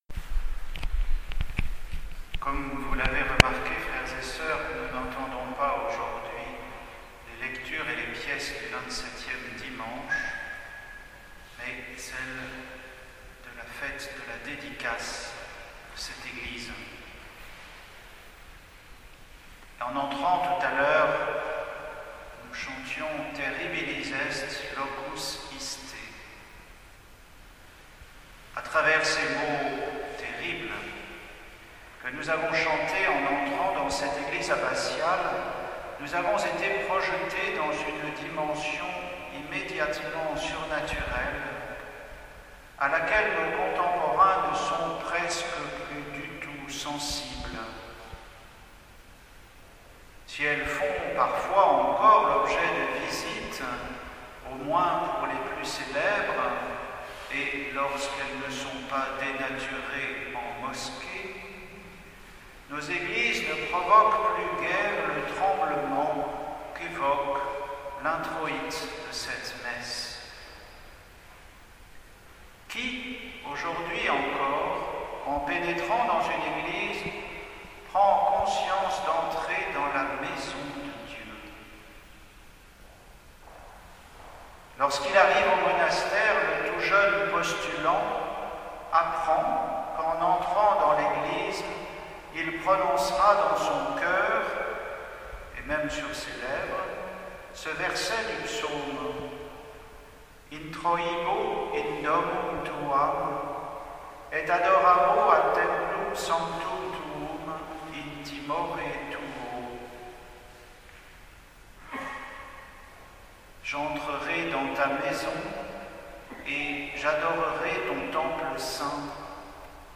Homélie pour la solennité de l'anniversaire de la dédicace de l'église abbatiale, 4 octobre 2020